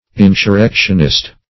Insurrectionist \In`sur*rec"tion*ist\, n.